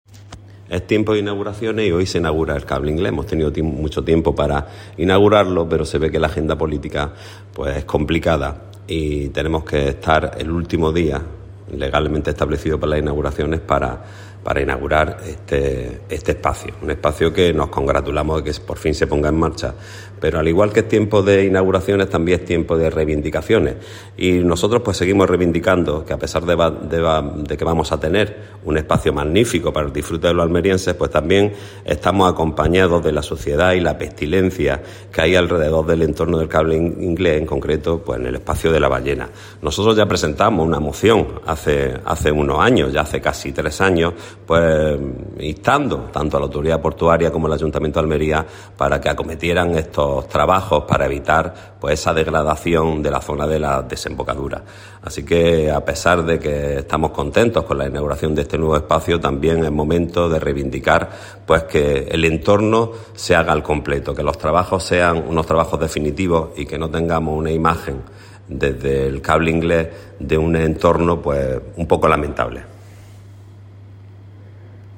Declaraciones Rafa Burgos